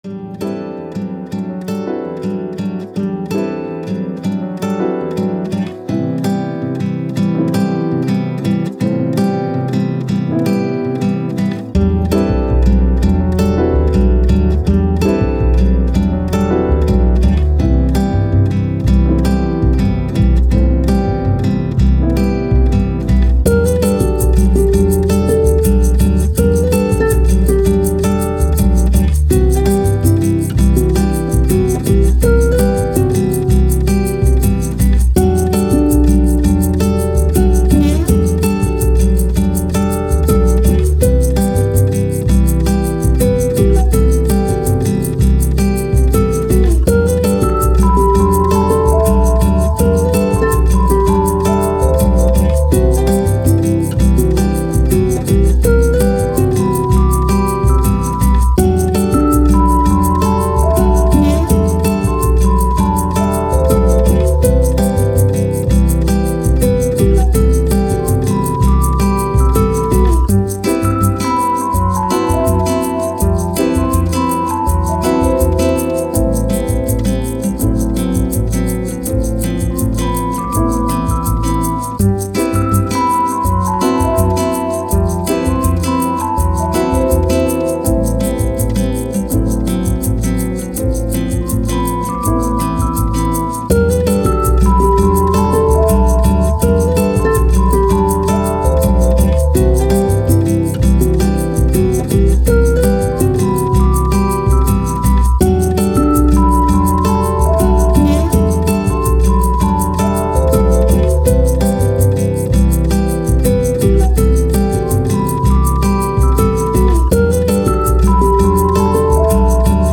Bossa Nova, Jazz, Chilled, Elegant, Landscapes